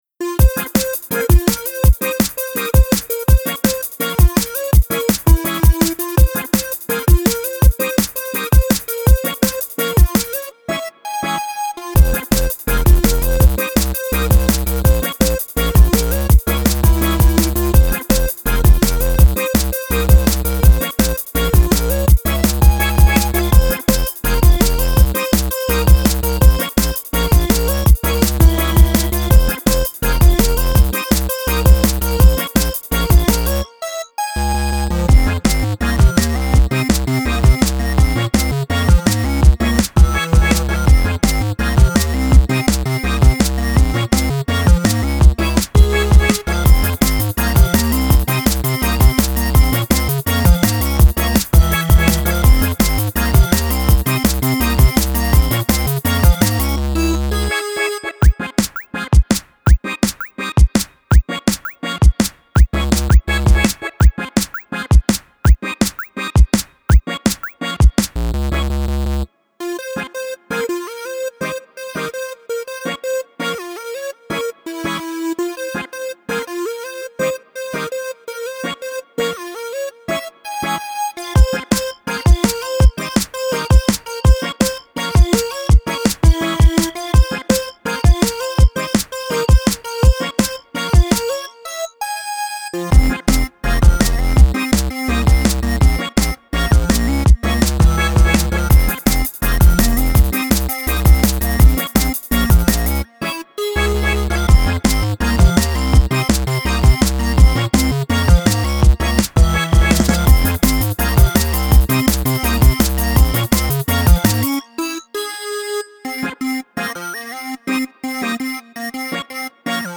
Uptempo 8-bit drum'n'bass skank.